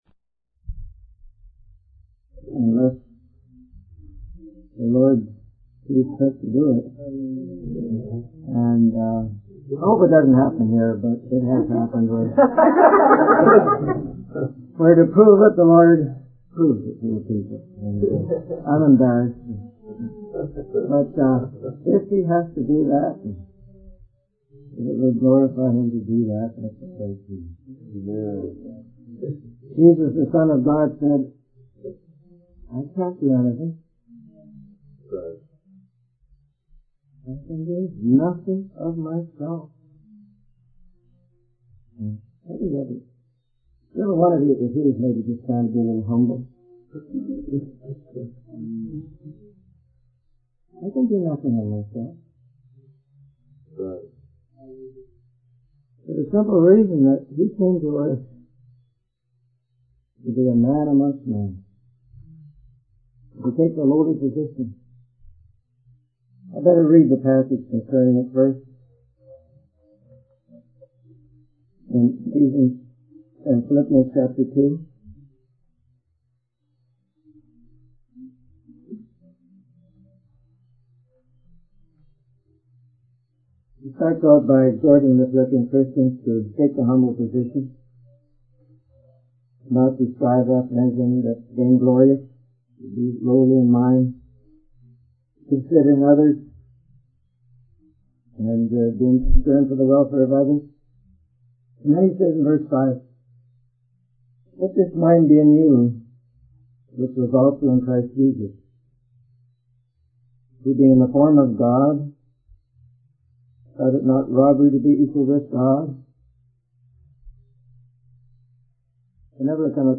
In this sermon, the speaker emphasizes the concept of being a bond slave to God, just as Jesus was.